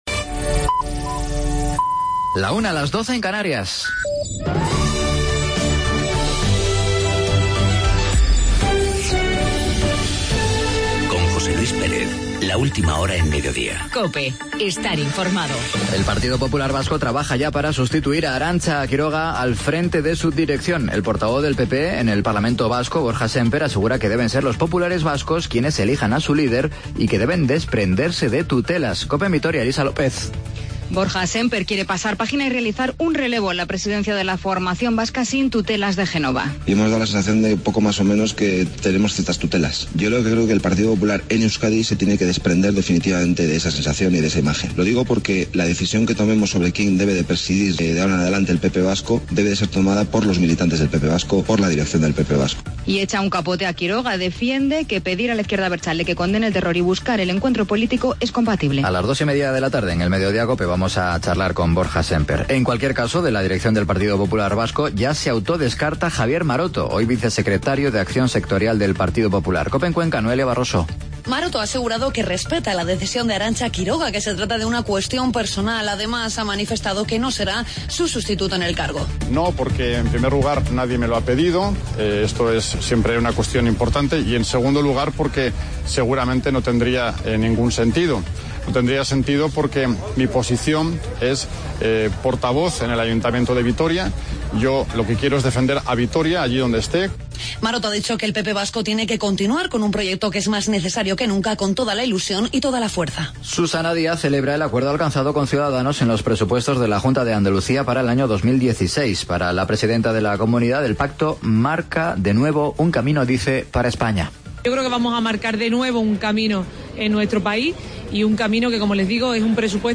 Boletín informativo